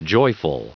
Prononciation du mot joyful en anglais (fichier audio)
Prononciation du mot : joyful